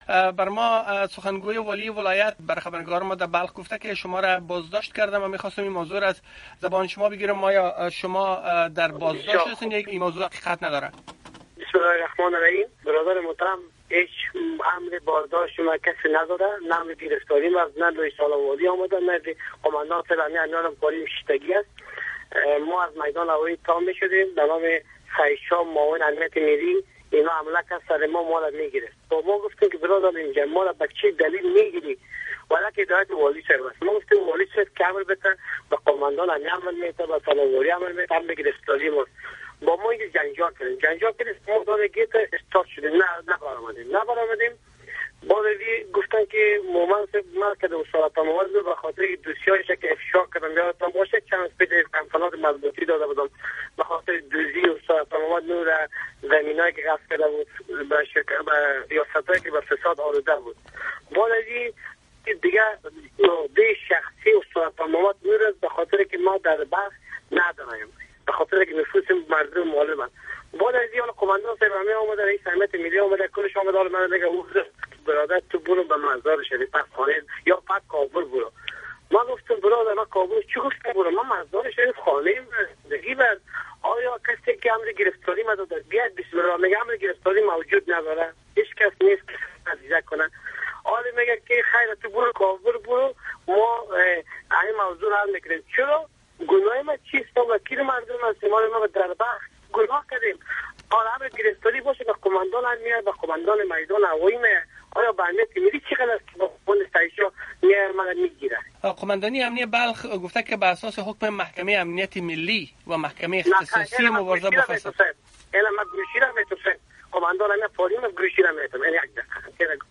صحبت با آصف مهمند عضو شورای ولایتی بلخی و سید کمال سادات فرماندۀ امنیۀ بلخ را ازینجا بشنوید: